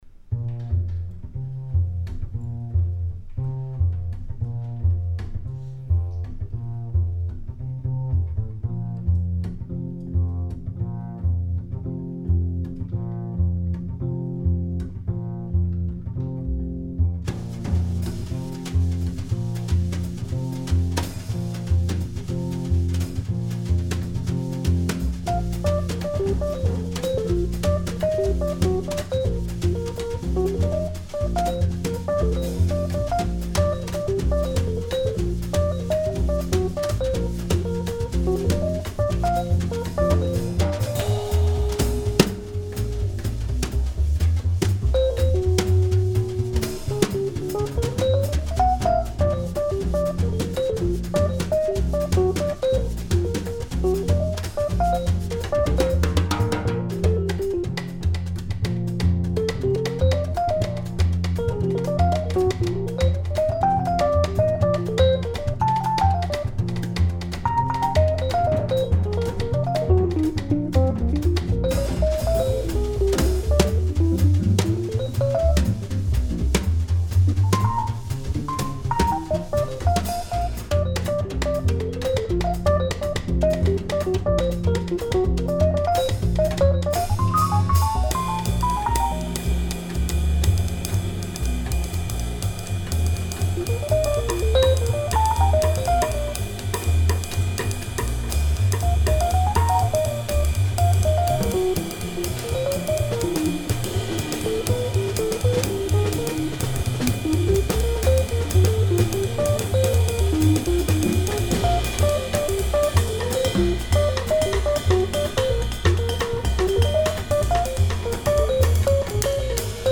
Belgian jazz meeting
rhodes